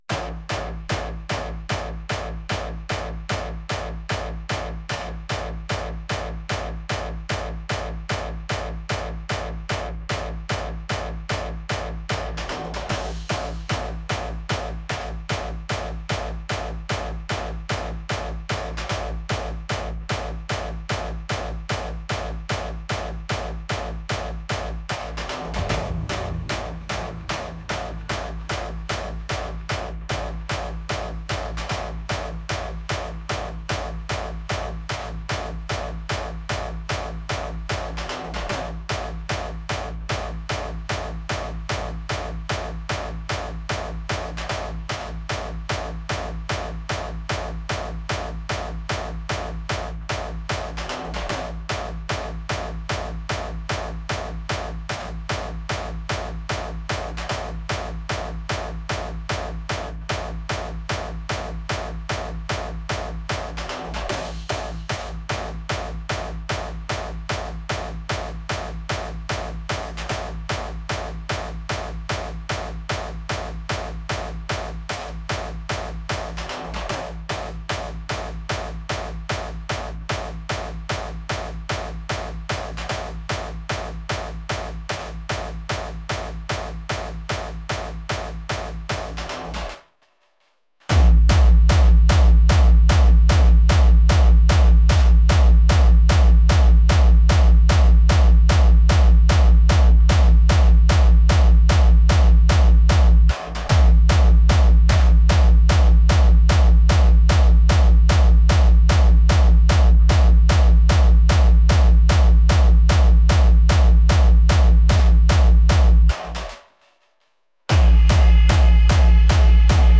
aggressive